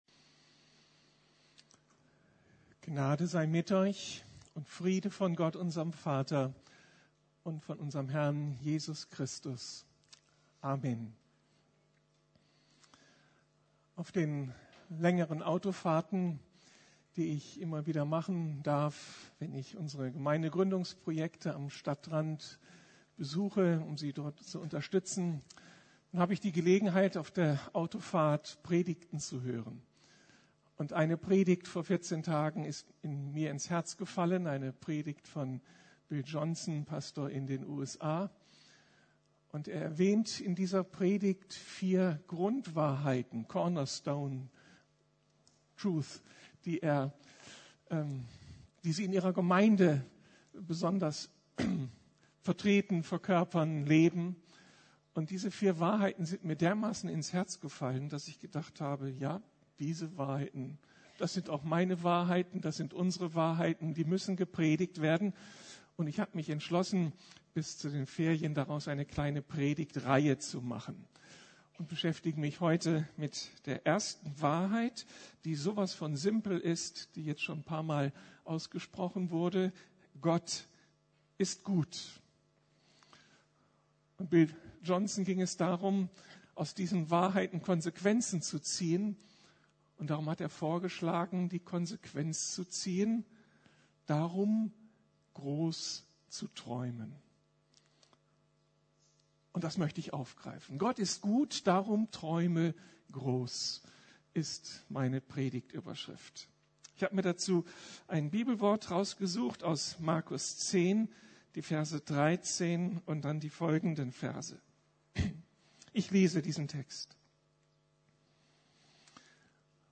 Gott ist gut, darum träume groß! ~ Predigten der LUKAS GEMEINDE Podcast